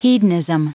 Prononciation du mot hedonism en anglais (fichier audio)
Prononciation du mot : hedonism